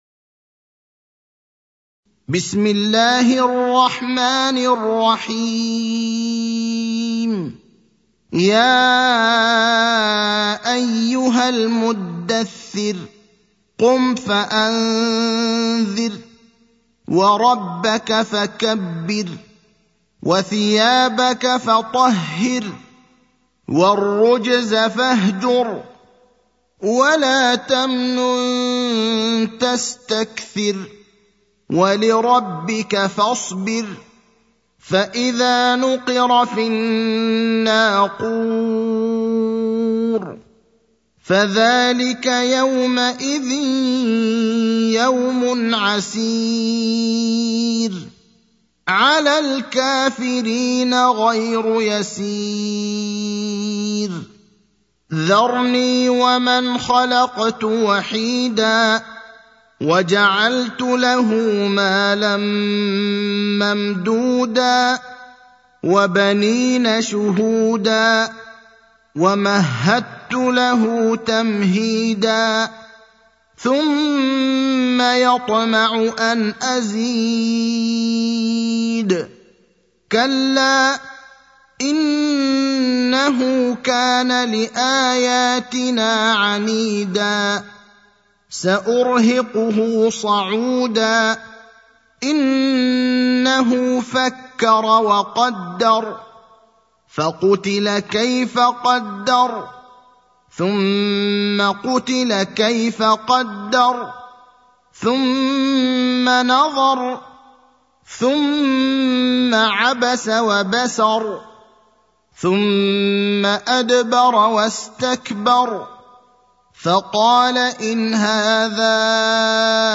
المكان: المسجد النبوي الشيخ: فضيلة الشيخ إبراهيم الأخضر فضيلة الشيخ إبراهيم الأخضر المدثر (74)